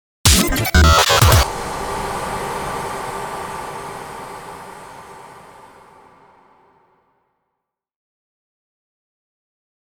FX-044-IMPACT COMBO
FX-044-IMPACT-COMBO.mp3